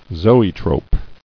[zo·e·trope]